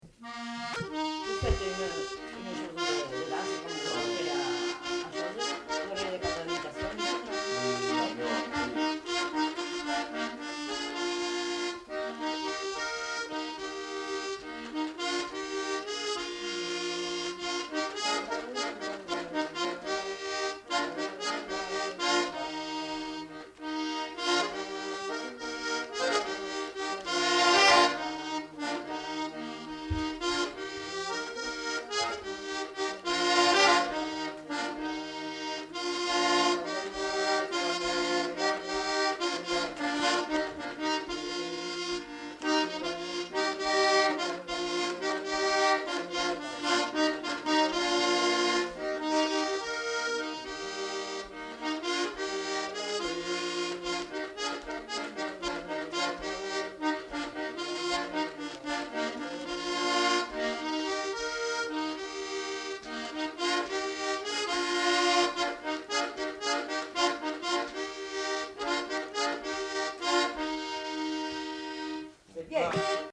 Lieu : Auriac-sur-Vendinelle
Genre : morceau instrumental
Instrument de musique : accordéon
Danse : valse